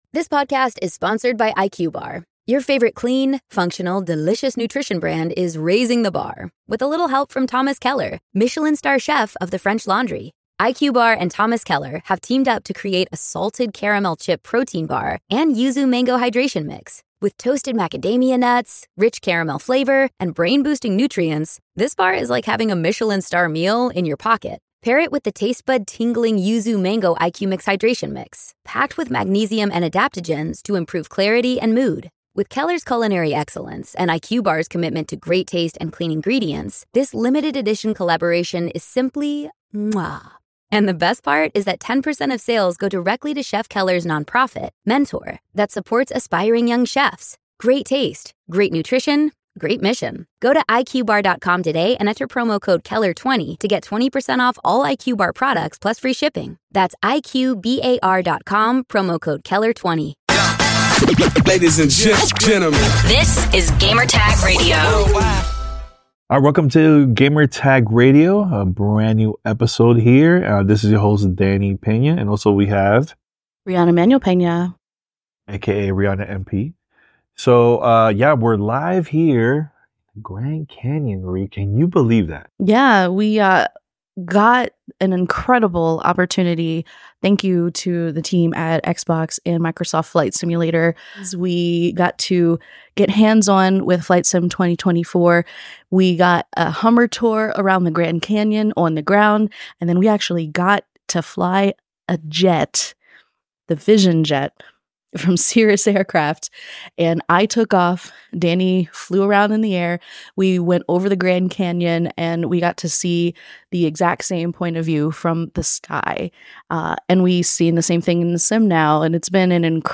Microsoft Flight Simulator 2024: Developer Interviews and Hands-On
Join us as we share our hands-on impressions of Microsoft Flight Simulator 2024. Plus, exclusive interviews with the developers, diving into the latest new features and much more!